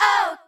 okItsOngirls1.ogg